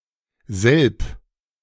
Selb (German pronunciation: [zɛlp]